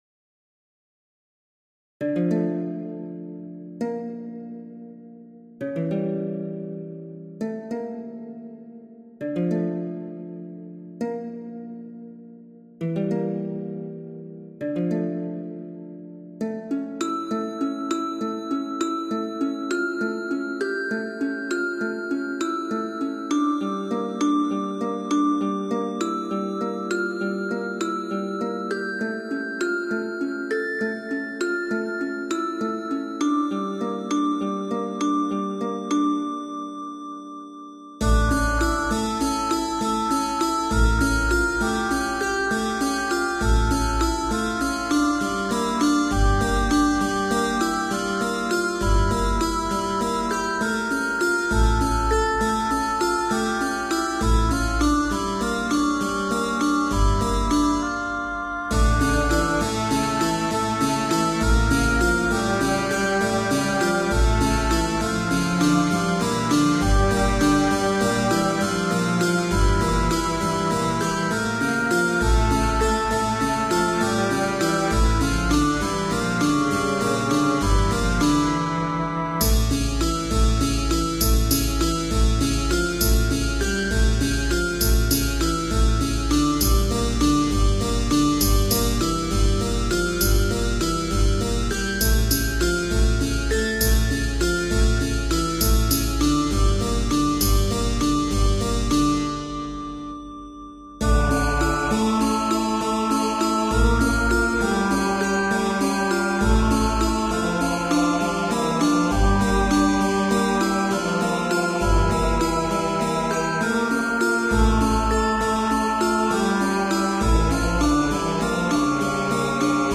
- Ambient
The link above are in low quality, if you want a better sound, you must download the FLAC ones from OGA.